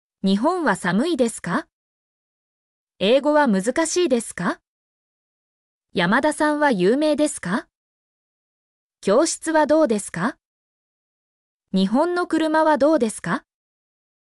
mp3-output-ttsfreedotcom-19_6uS1hvZy.mp3